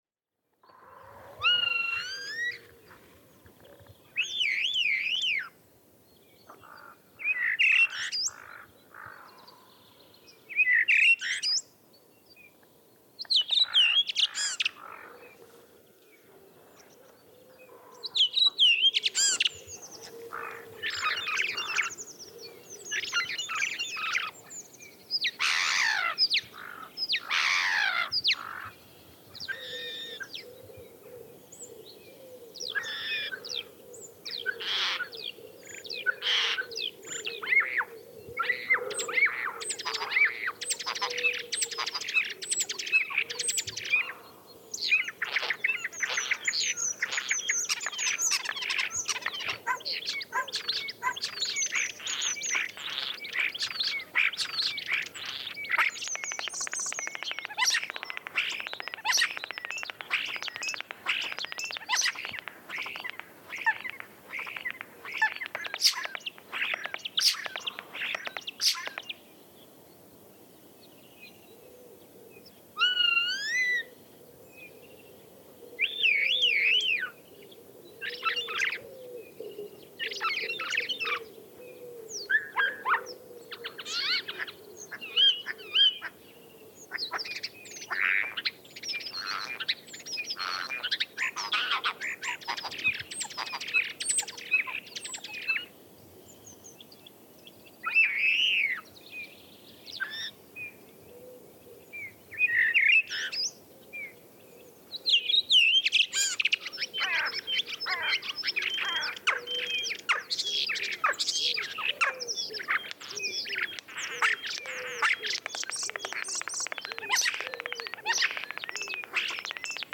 Sturnus vulgaris - Estornino
Estornino.mp3